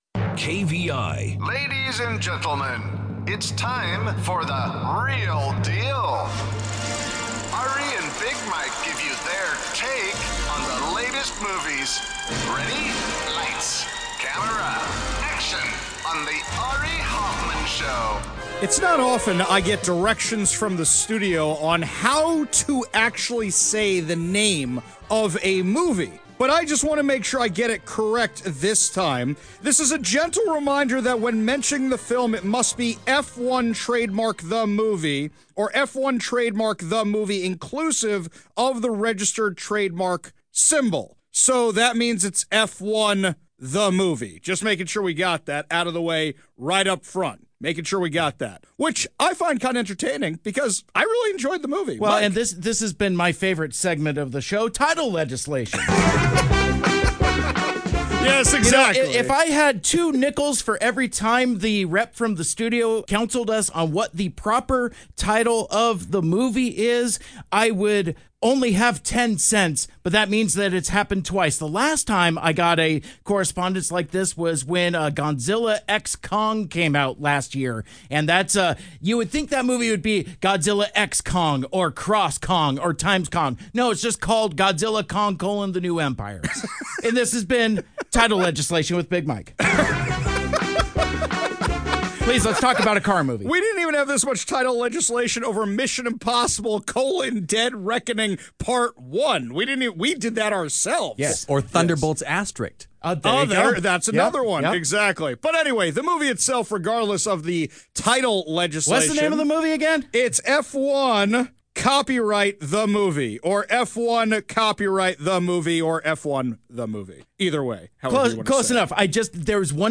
Find out in this lively review!
6-23-f1-the-movie-review.mp3